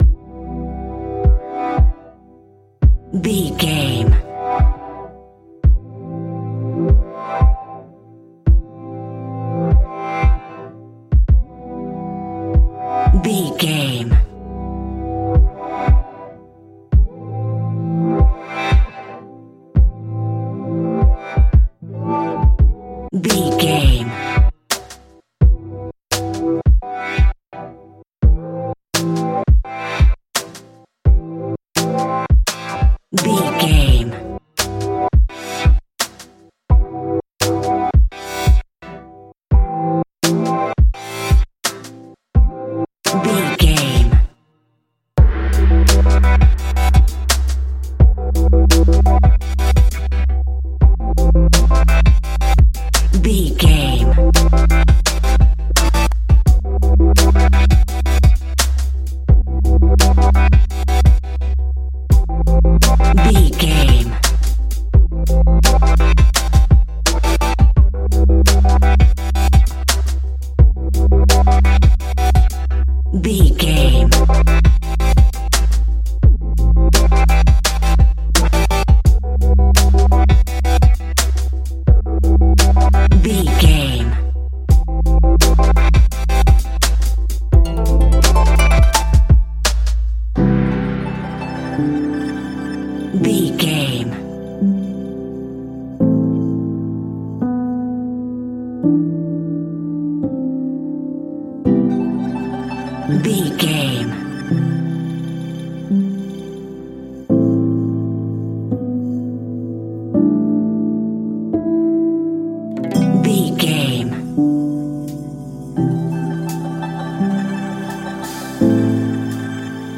Ionian/Major
Slow
dreamy
tranquil
synthesiser
drum machine